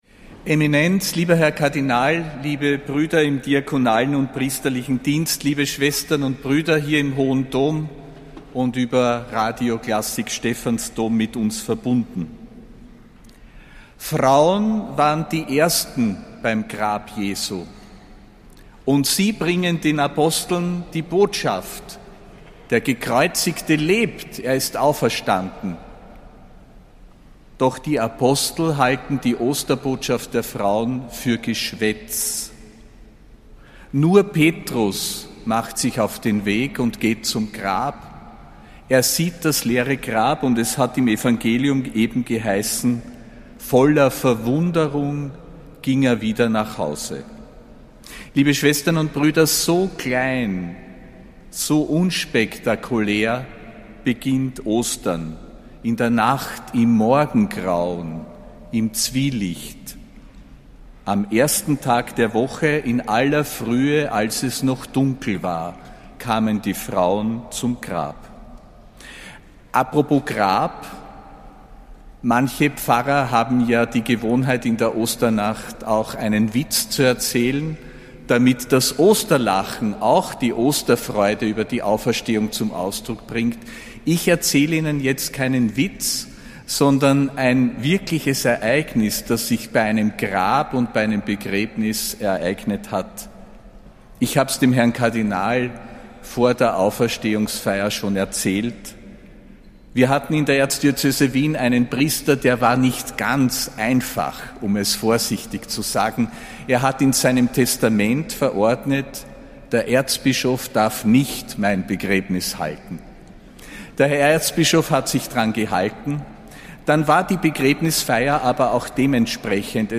Predigt zur Osternacht (19. April 2025)